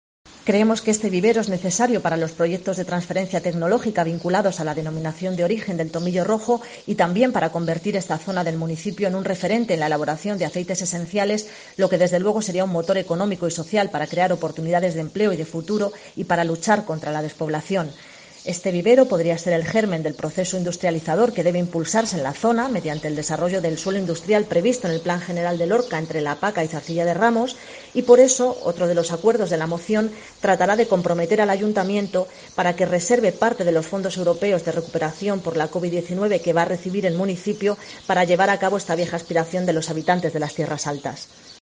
Gloria Martín, edil de IU Verdes sobre tomillo rojo